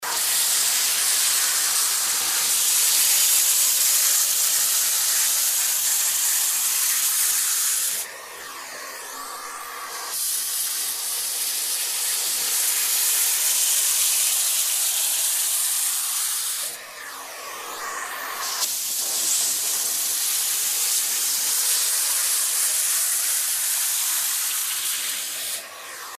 Звуки пылесоса
Вы можете слушать онлайн или скачать монотонный гул для маскировки шума, создания фона или использования в творческих проектах.